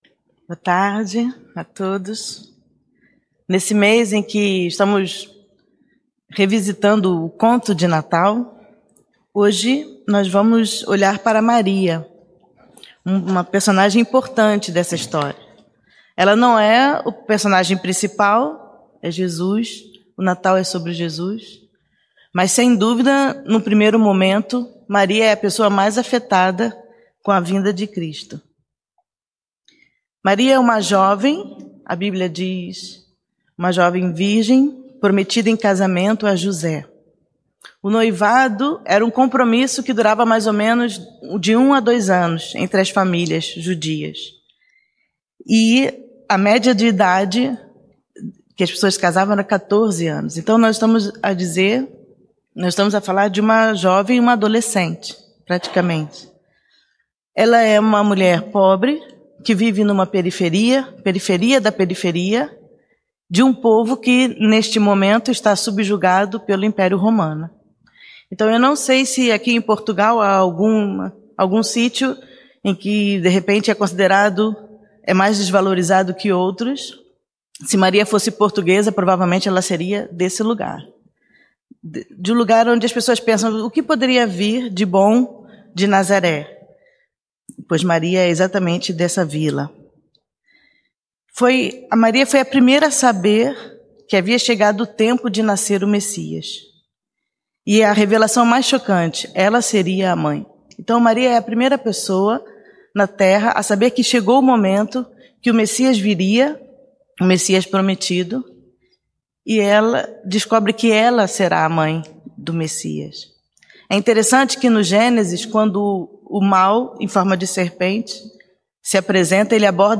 mensagem bíblica Deus chama homens e mulheres comuns, sem grandes prerrogativas para o mundo, para uma jornada de fé e redenção.